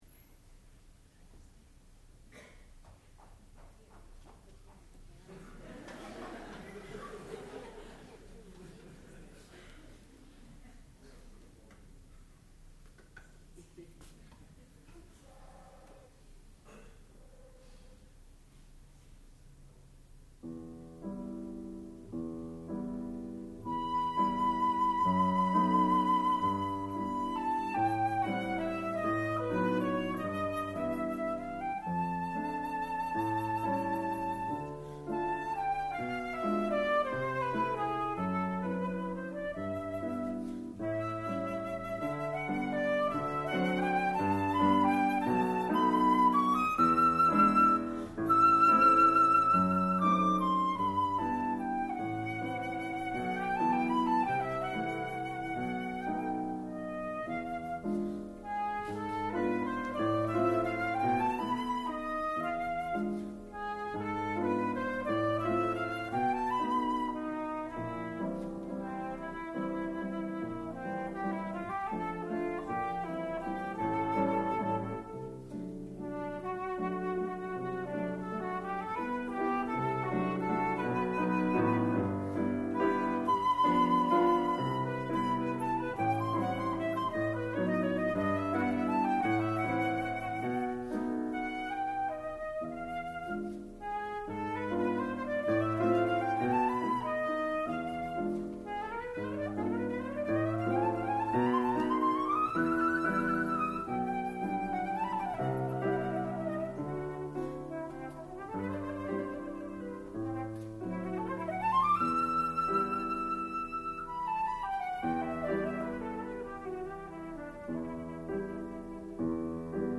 sound recording-musical
classical music
Flute
Piano